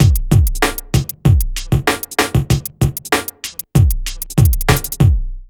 3RB96BEAT2-L.wav